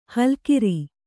♪ halkiri